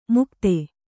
♪ mukte